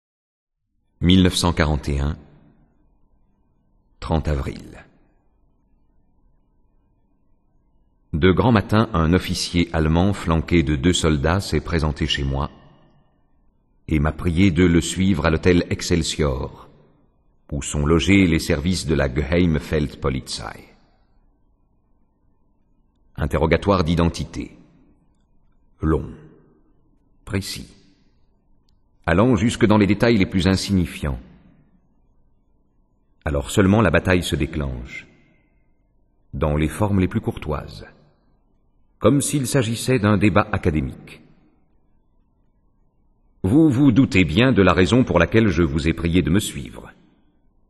Click for an excerpt - Adieu aux vivants - Journal de détention de Fernand Rahier